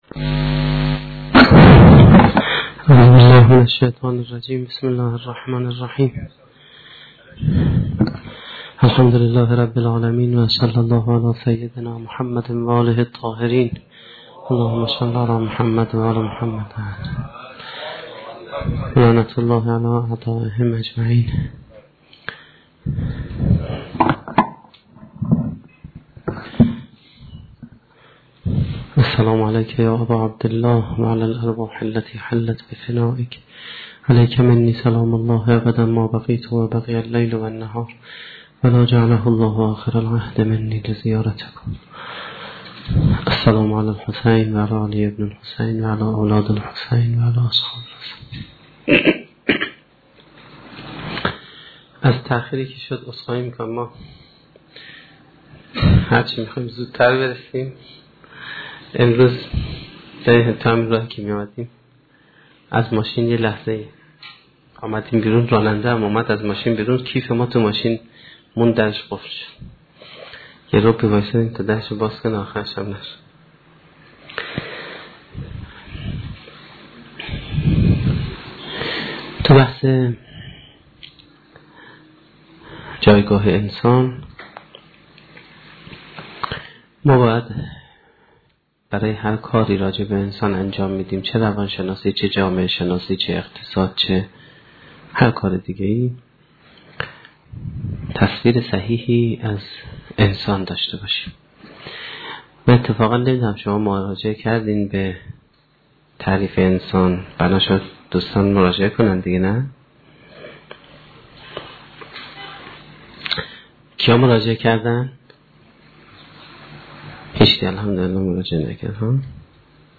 سخنرانی شب 20 محرم1435-1392